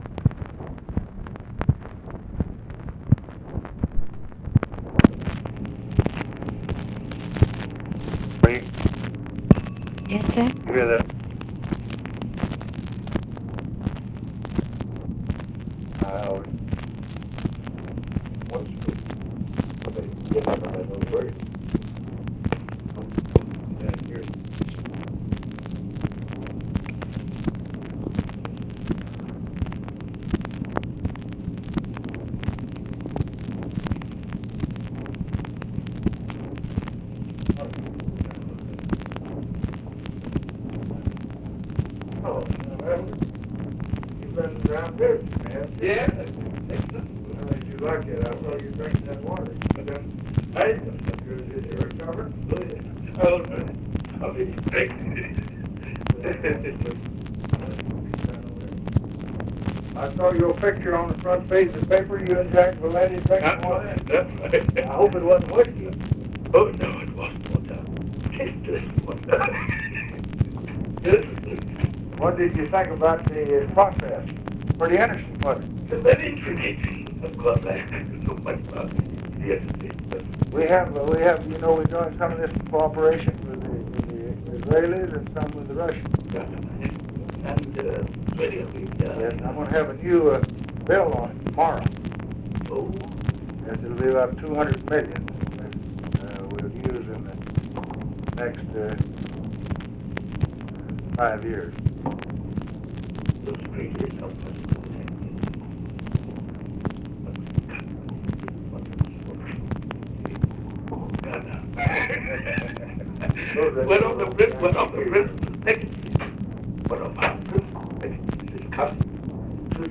Conversation with MCGEORGE BUNDY, August 6, 1965
Secret White House Tapes